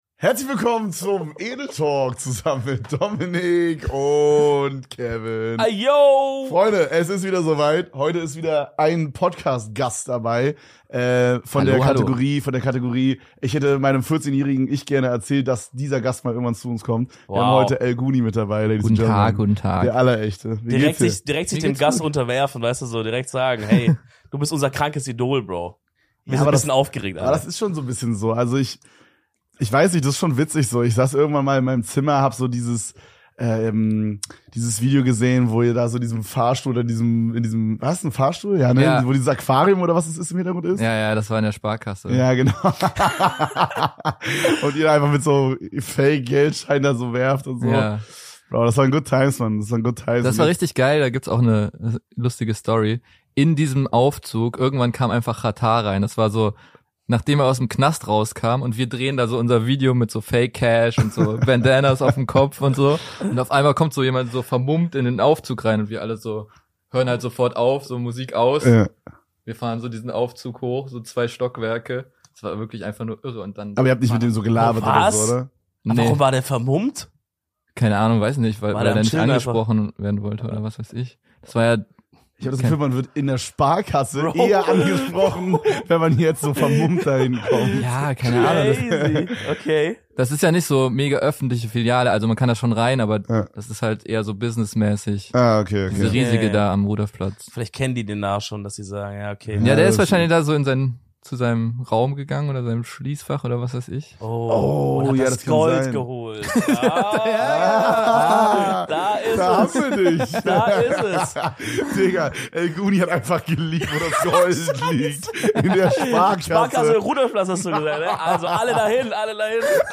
Heute hatten wir den einzig wahren LGoony im Studio zu Gast.